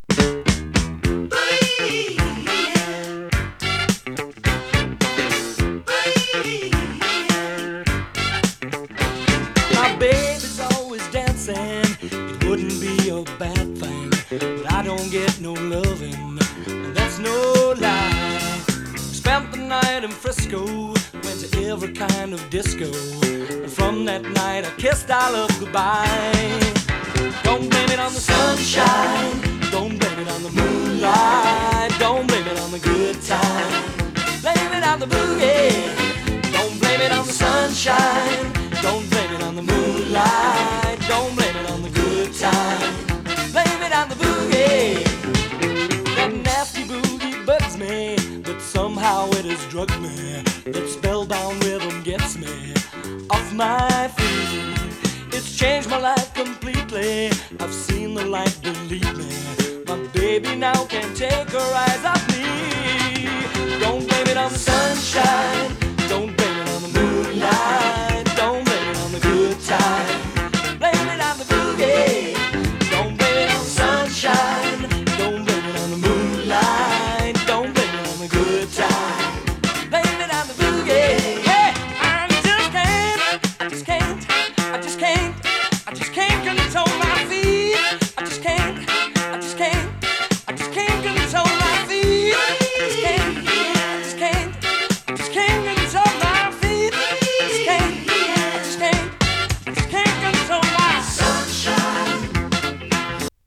SOUL FUNK